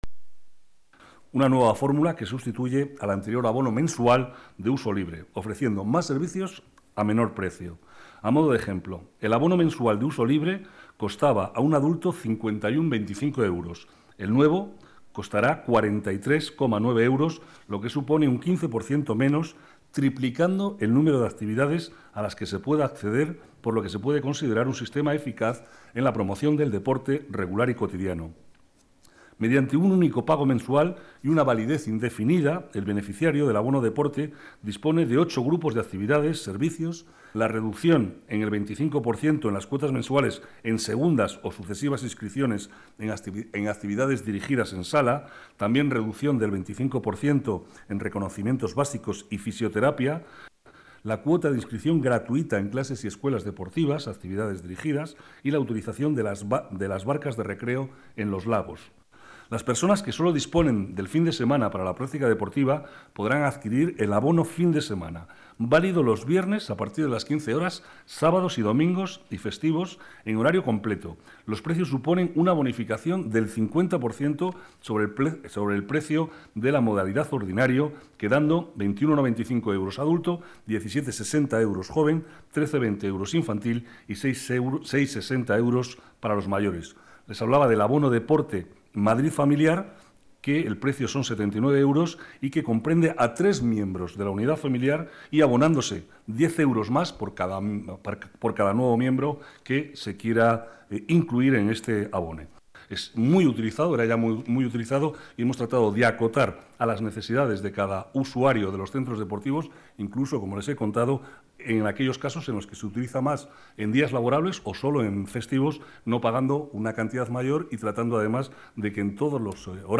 Nueva ventana:Declaraciones de Cobo sobre Abono Deportes